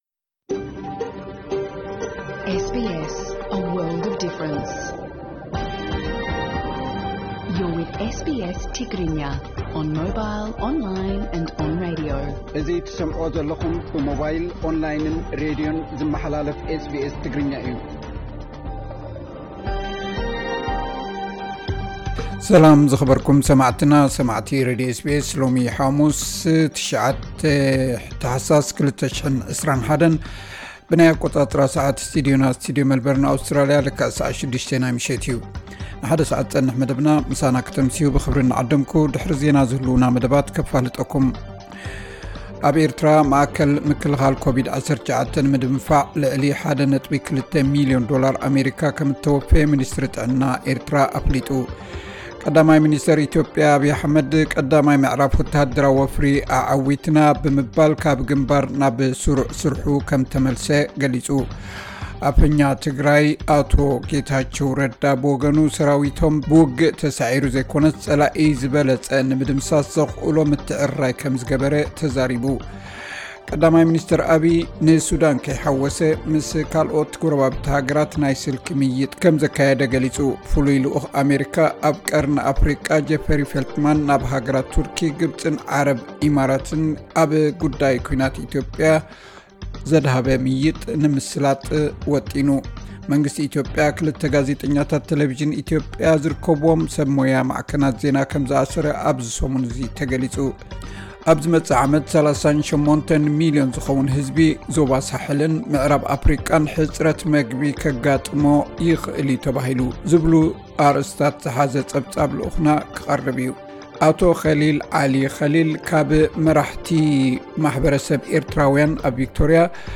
ዕለታዊ ዜና SBS ትግርኛ (09 ታሕሳስ 21)